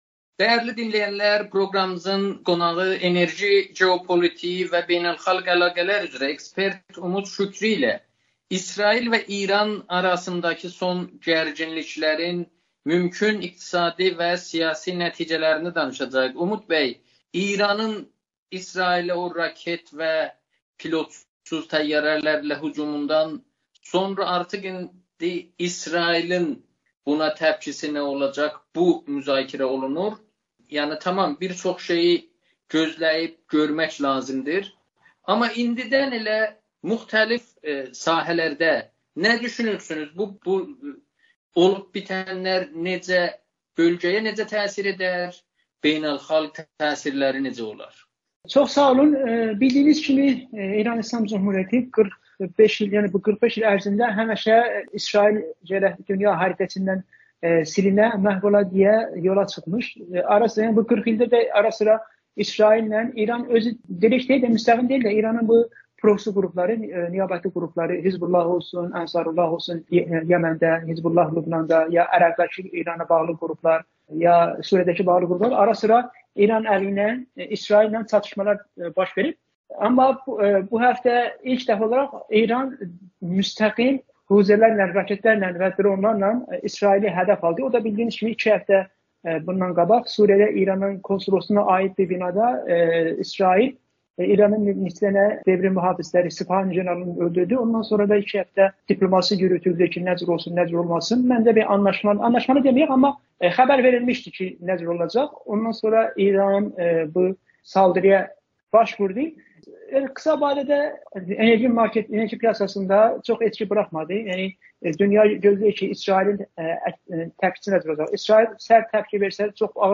müsahibəsində